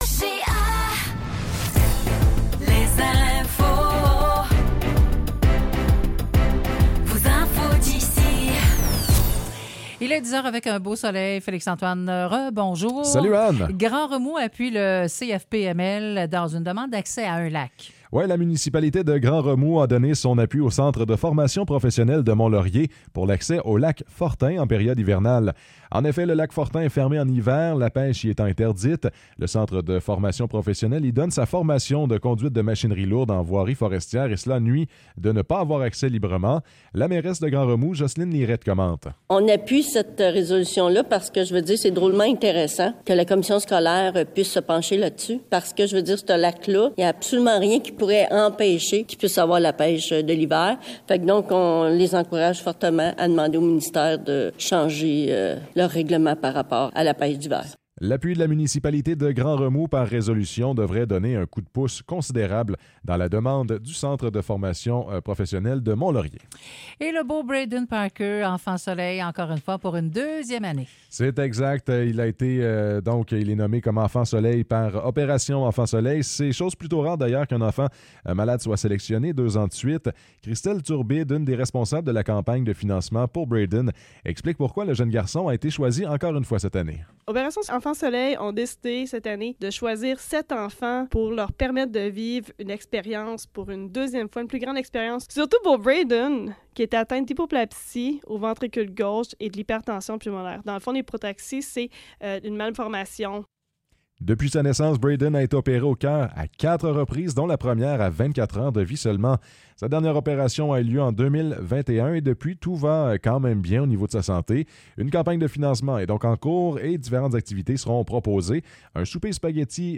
Nouvelles locales - 30 janvier 2024 - 10 h